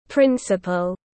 Hiệu trưởng tiếng anh gọi là principal, phiên âm tiếng anh đọc là /ˈprɪn.sə.pəl/.